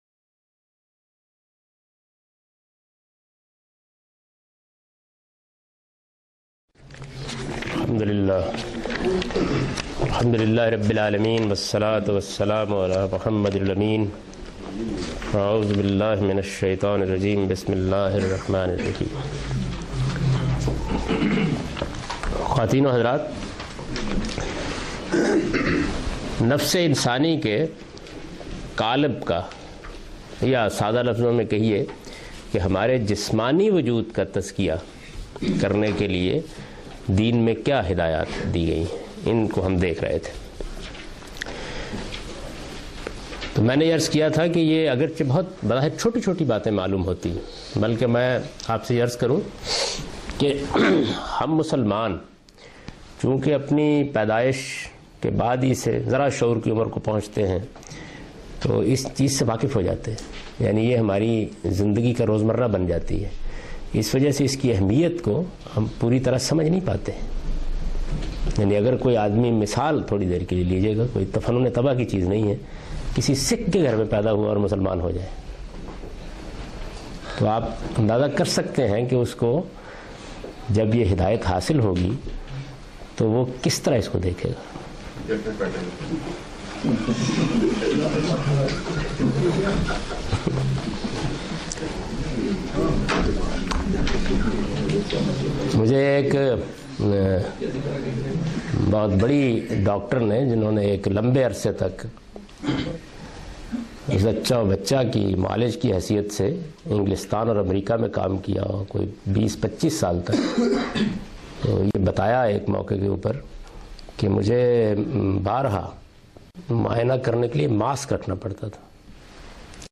In this lecture he teaches the topic 'The True Religion'.